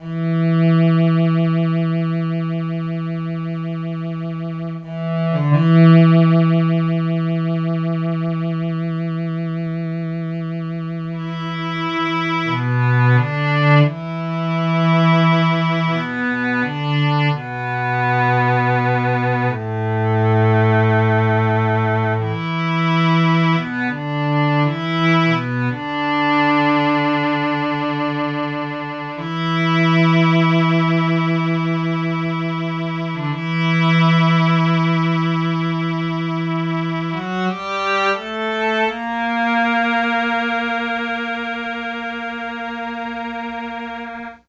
Track 02 - Cello.wav